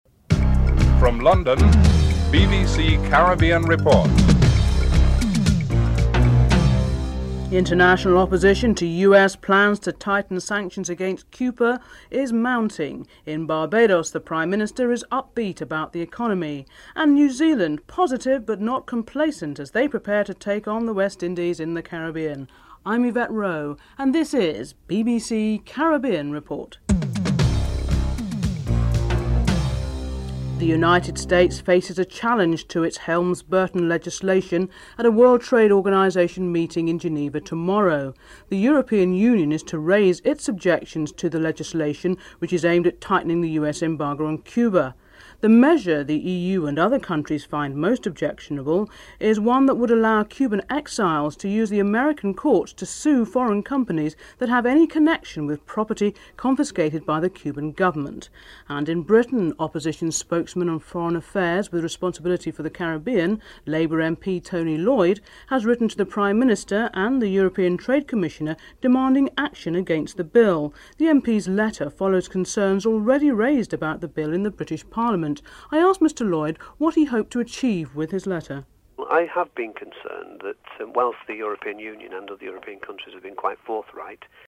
1. Headlines (00:00-00:30)
2. The international opposition to the US plans to tighten sanctions against Cuba is mounting. Labour Member of Parliament Tony Lloyd is interviewed (00:31-04:57)
5. In Barbados Prime Minister Owen Arthur is up beat about the economy. Prime Minister Owen Arthur is interviewed (08:53-10:30)